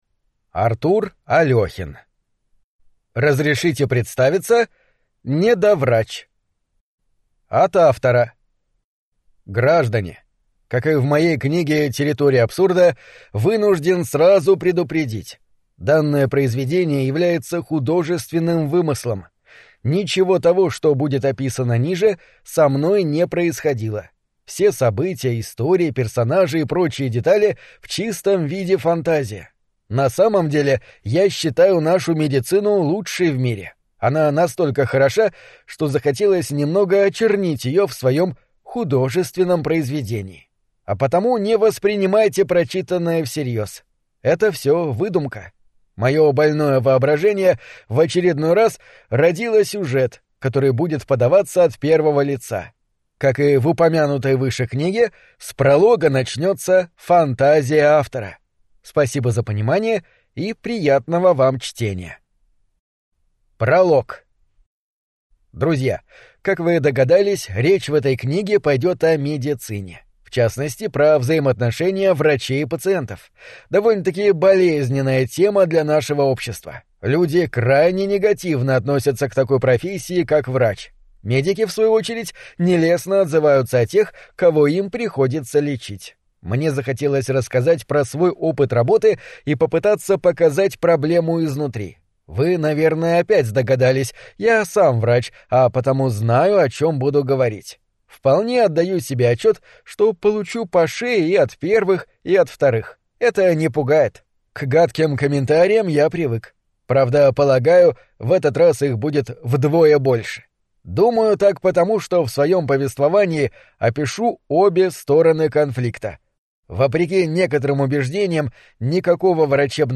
Аудиокнига Разрешите представиться: недоврач | Библиотека аудиокниг